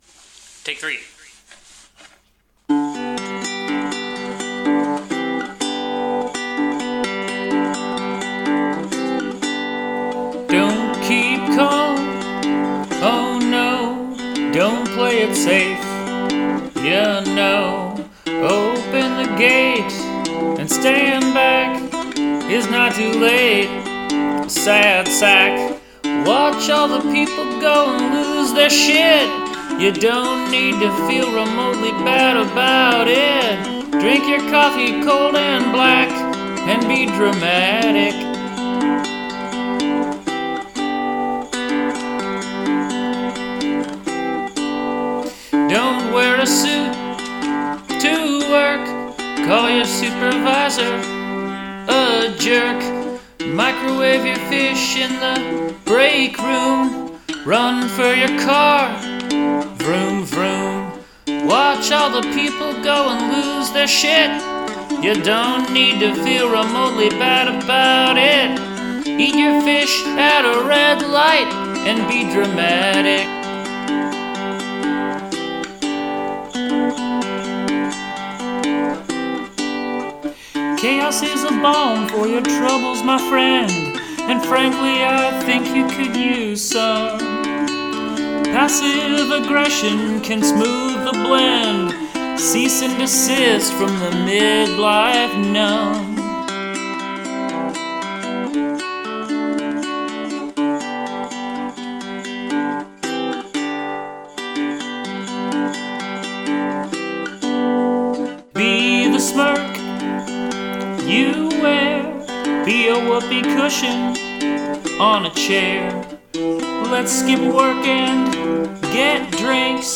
The fingerpicking in this playful rhythm highlights the wry and funny lyrics -- but there's definitely wisdom in there.
I like the way you create the accompaniment with the guitar and the way the vocals echo the rhythm. a great song - nice work